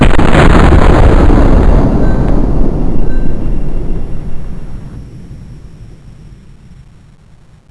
spas12-fire.wav